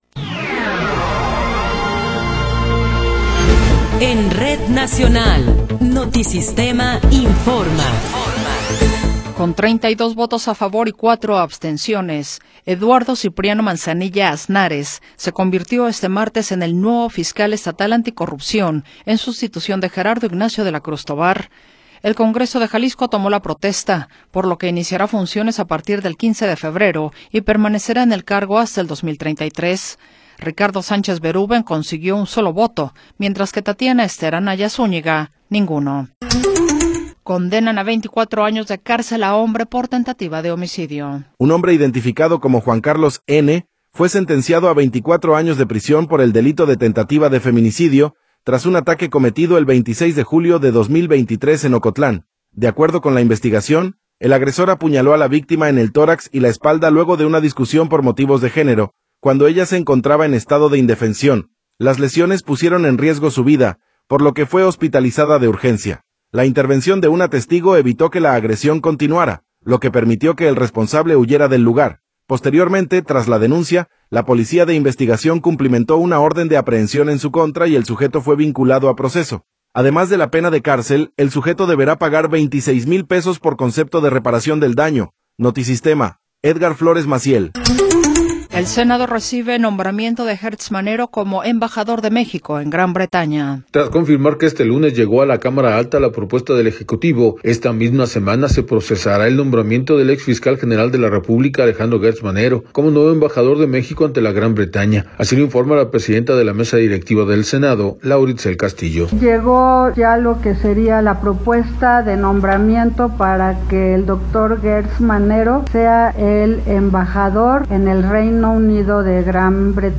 Noticiero 17 hrs. – 20 de Enero de 2026